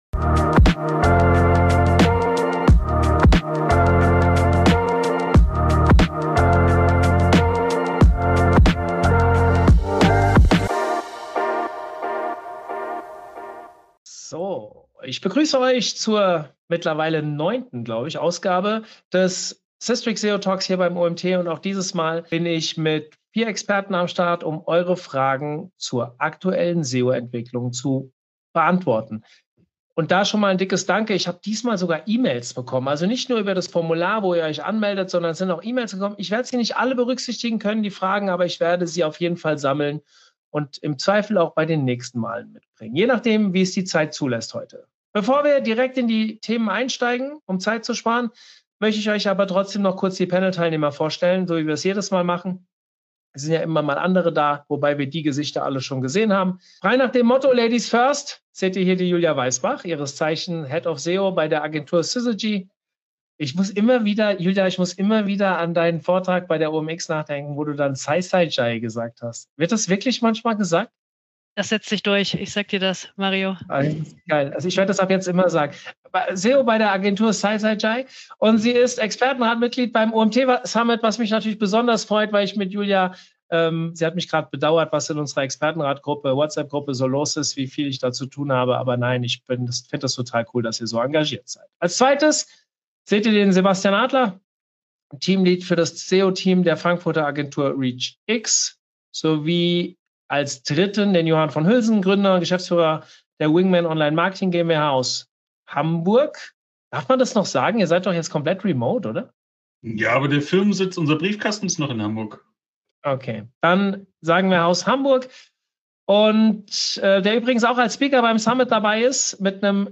Ausgewählte Fragen der Zuschauer:innen werden direkt in der Expertenrunde aufgegriffen und live beantwortet.